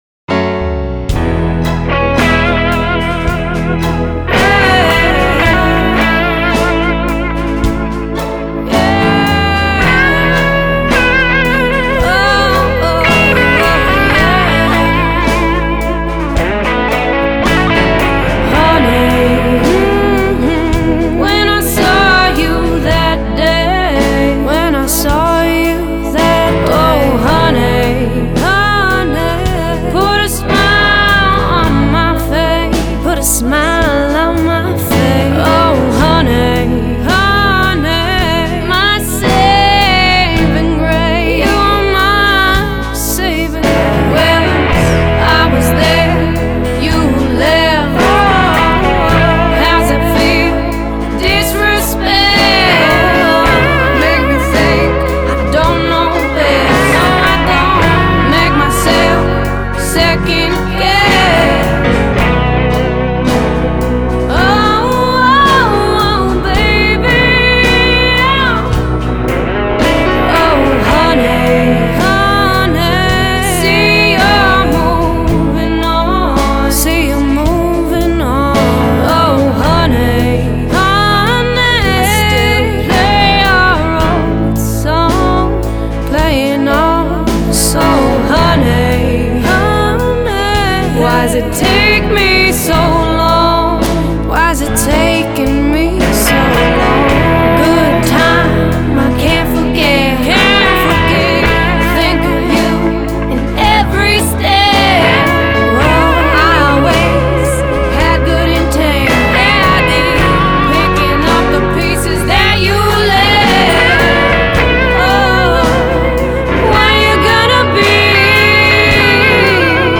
Хороший небольшой блюз-роковый альбом
он содержит смесь блюза, рока, кантри и R & B.